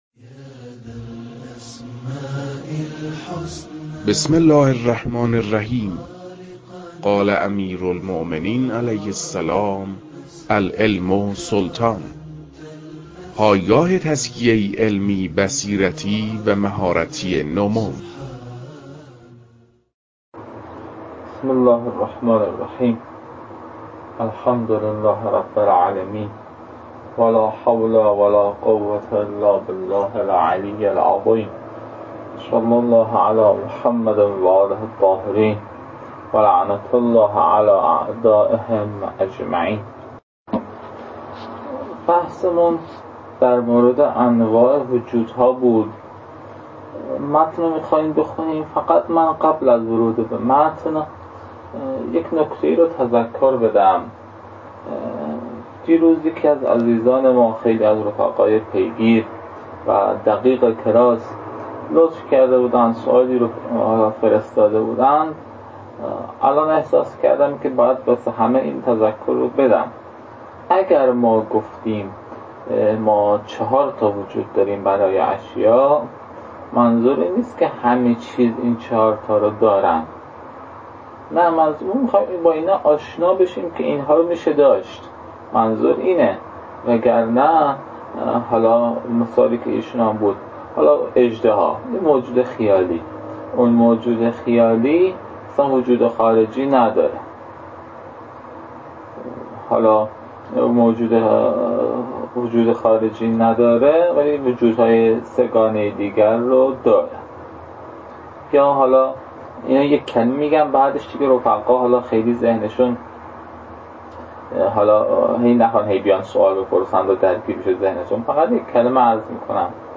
روخوانی متن اقسام چهارگانه وجود (2)